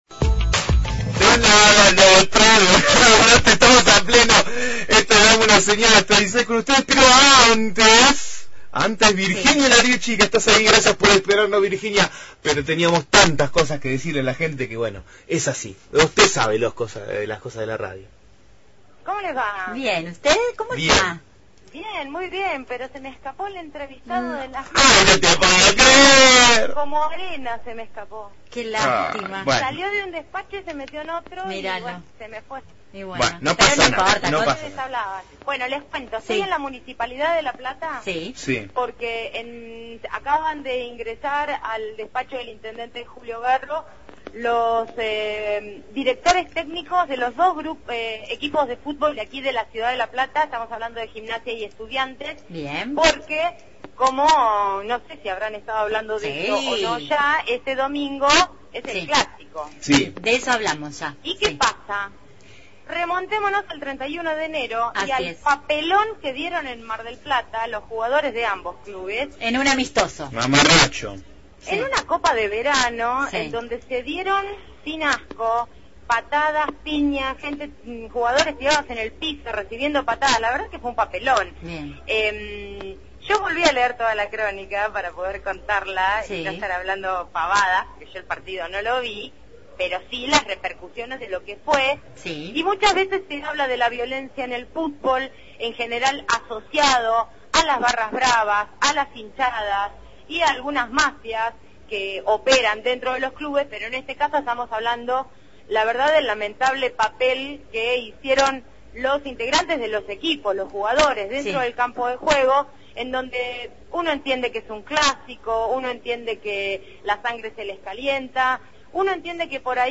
MÓVIL/ Reunión de los directivos de Gimnasia y Estudiantes previo al clásico – Radio Universidad